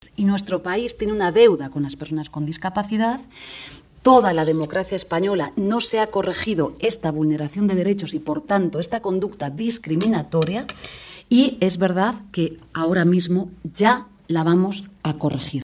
dijo formato MP3 audio(0,30 MB)Así lo manifestó en un diálogo organizado por Servimedia y la Unión de Profesionales y Trabajadores Autónomos (UPTA) bajo el título ‘Los retos y el futuro del trabajo autónomo’, celebrada en el marco del 35º aniversario de Servimedia.